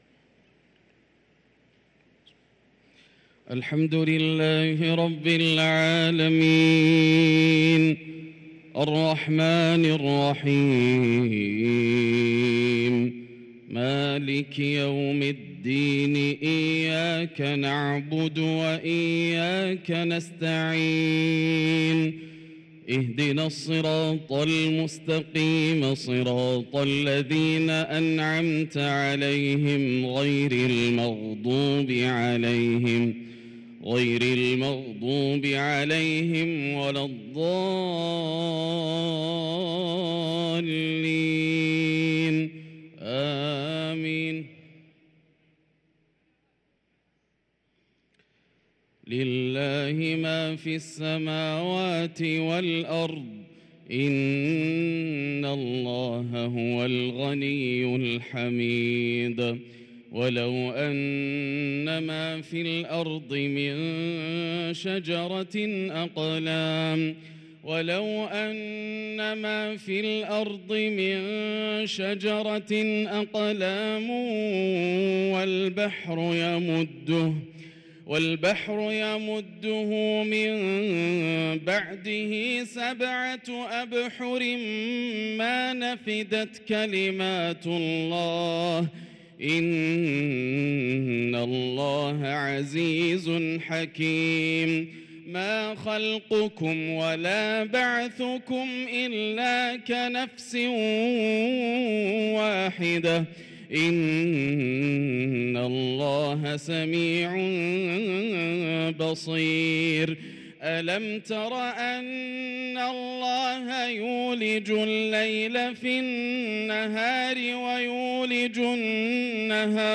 صلاة العشاء للقارئ ياسر الدوسري 17 ربيع الأول 1444 هـ